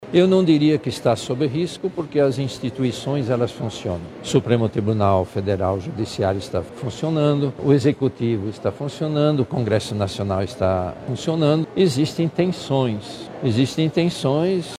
Durante uma recente declaração na Coletiva de Imprensa do Grito dos Excluídos 2025 em Manaus, o Cardeal Dom Leonardo Ulrich Steiner reforçou a importância da democracia como base para a convivência social e reafirmou a confiança nas instituições brasileiras.